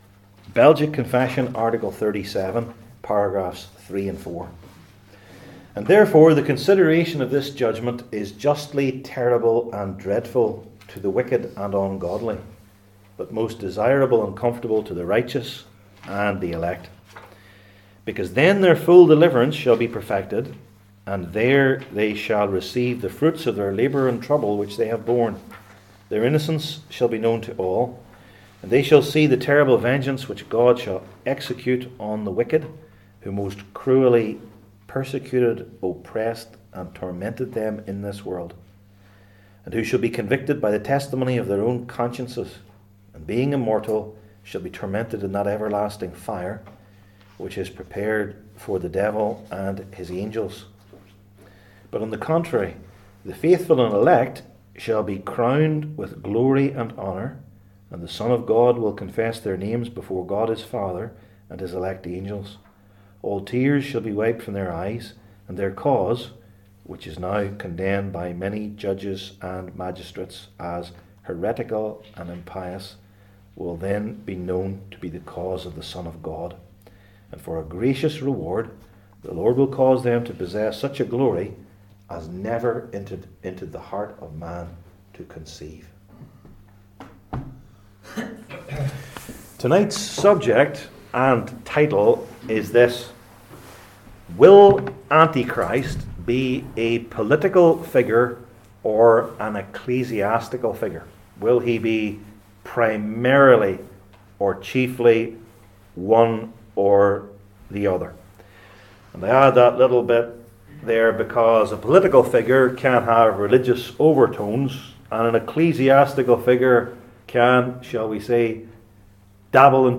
Revelation 13:1-10 Service Type: Belgic Confession Classes THE LAST JUDGMENT …